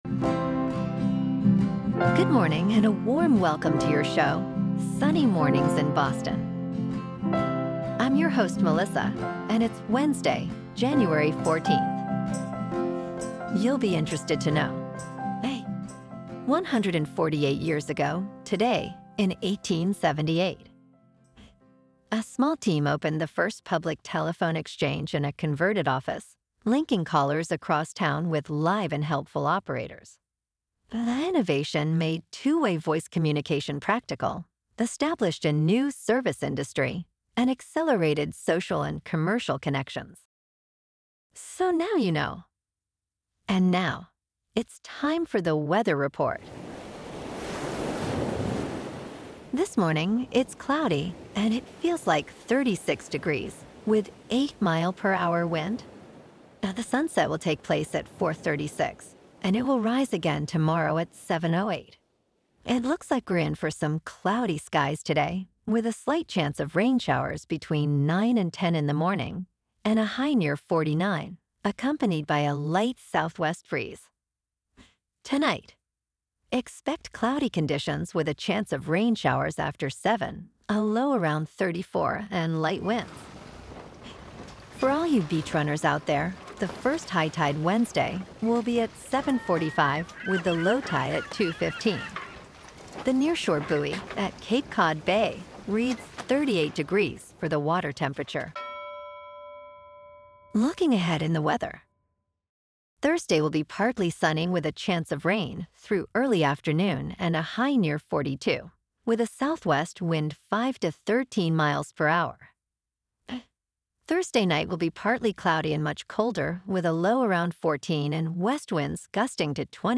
Daily Boston News, Weather, Sports.
The #1 Trusted Source for AI Generated News™